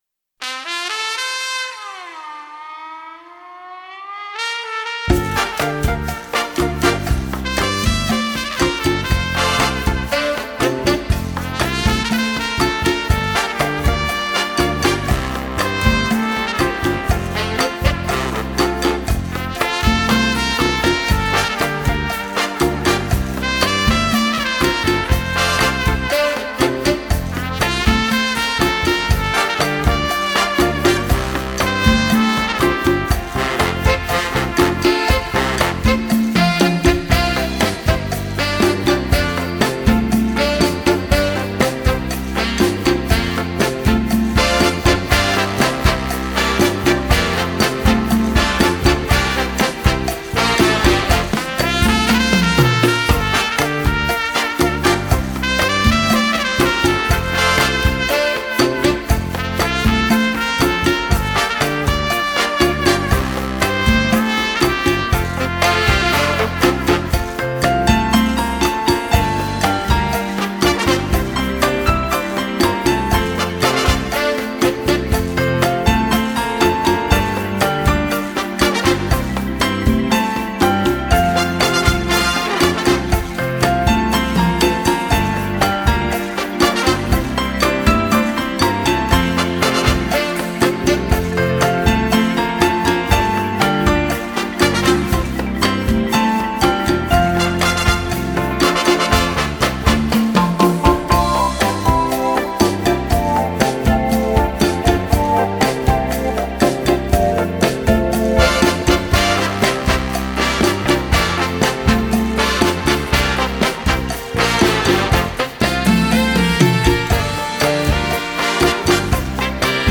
Cha-Cha